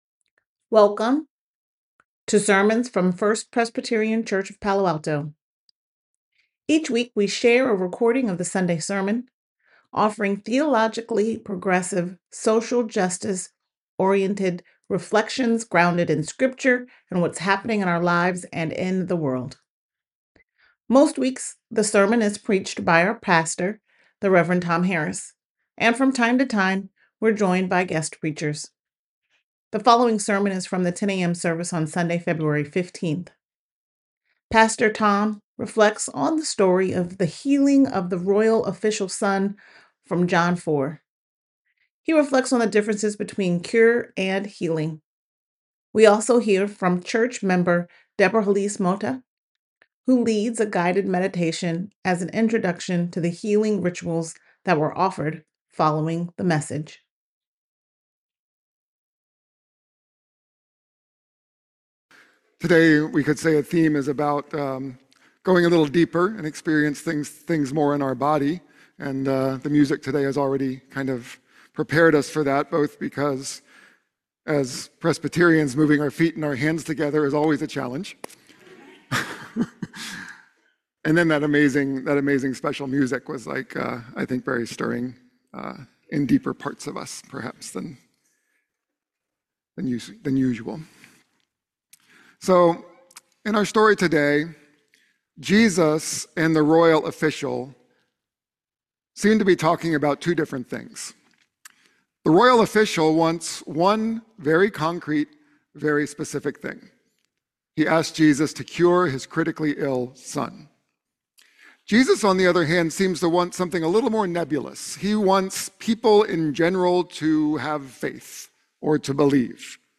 The following sermon is from the 10 a.m. service on Sunday February 15th.